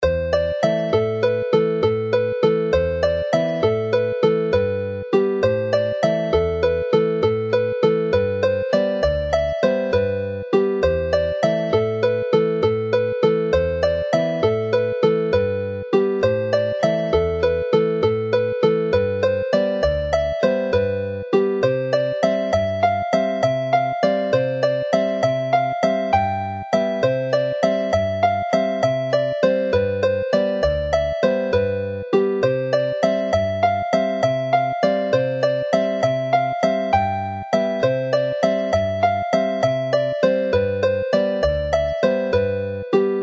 The set concludes with the rip-roaring slip jig Neidod Twm Bach (little Tom's prank) from the excellent collection of 100 dance tunes in the second book of tunes collected from the National Library archives by Robin Huw Bowen and published by the Welsh Folk Dance Society, Cadw Twmpath.
Play the tune slowly